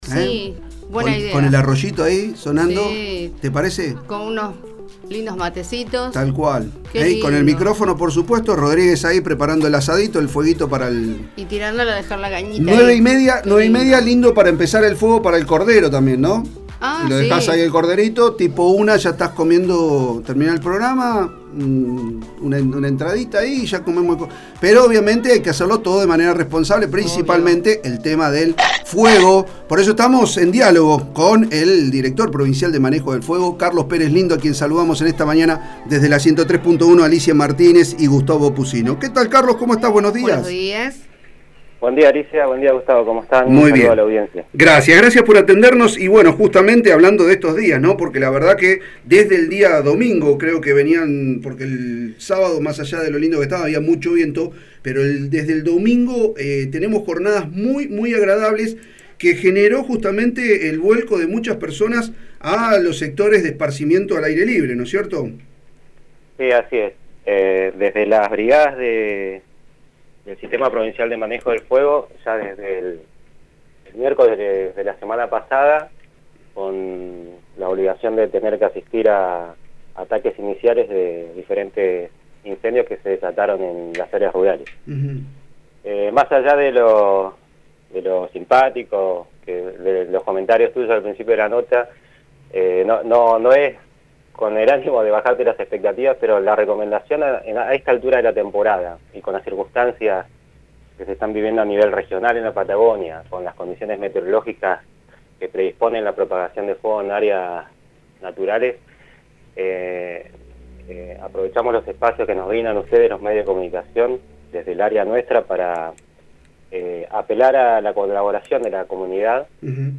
El Director Provincial de Manejo del Fuego, Carlos Perezlindo, en diálogo con el programa “La Otra Mañana” de la 103.1, la radio pública fueguina, señaló que las condiciones ambientales actuales son muy propicias para producir incendios forestales por lo que volvió a solicitar a la comunidad en general que no realice fogones en lugares no habilitados.